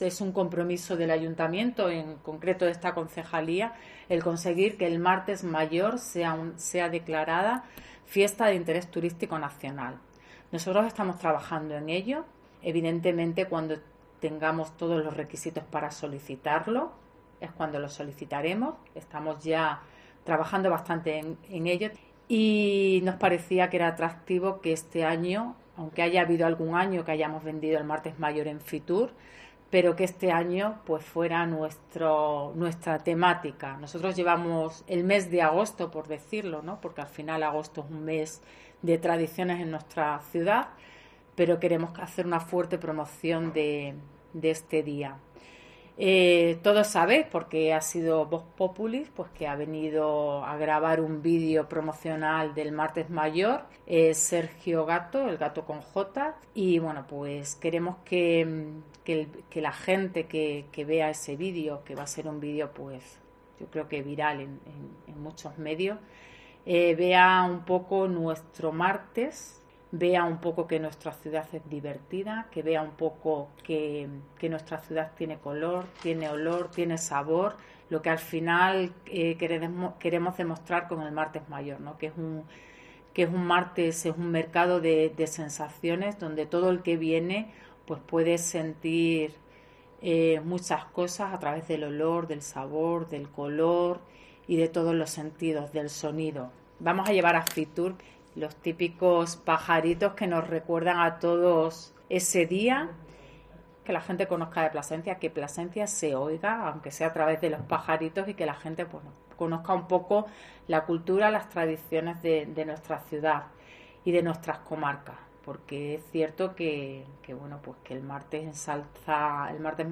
Belinda Martín, concejal turismo de Plasencia sobre Fitur